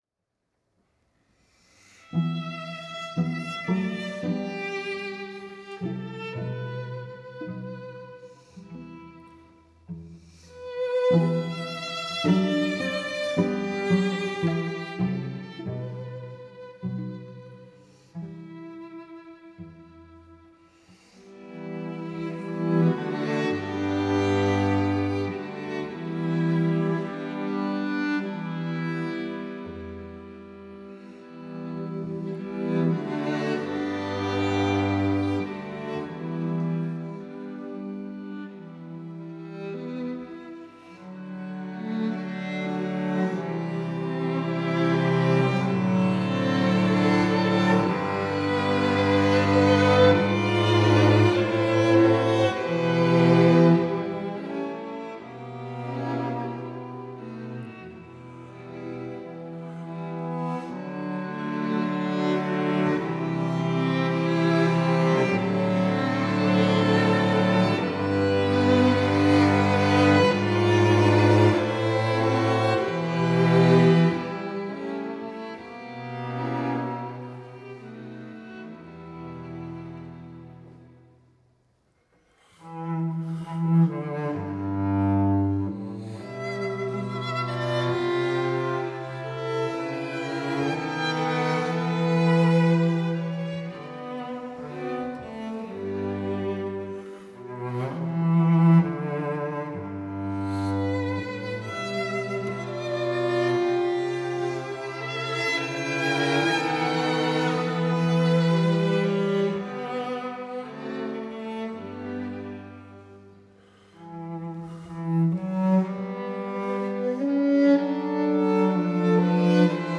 viool
altviool
cello) — voorjaarseditie 2022.